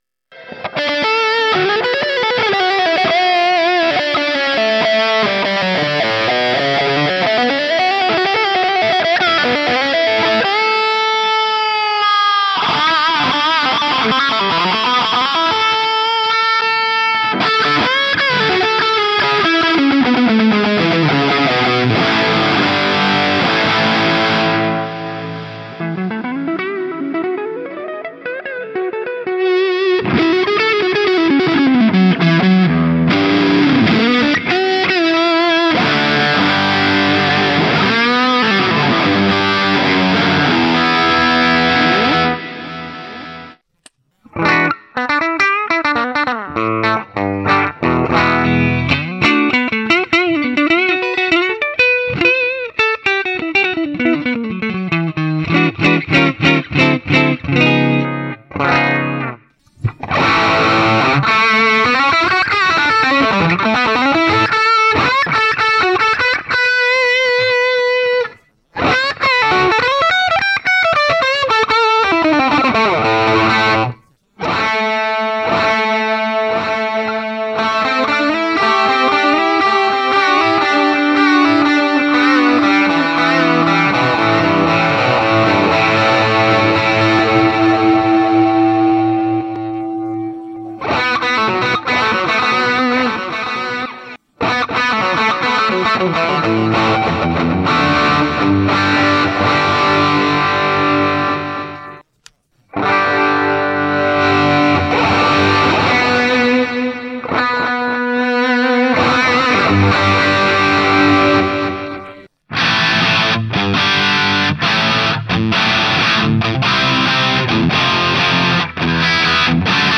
MOOER GE150 Electric Guitar Amp Modelling Multi Effects Pedal Portable Multi Effects Processor with Expression & IR Loading
mooer_ge150_multi_effects.mp3